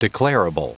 Prononciation du mot declarable en anglais (fichier audio)
Prononciation du mot : declarable